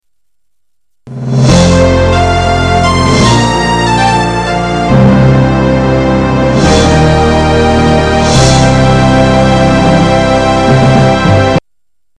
2. 背景音乐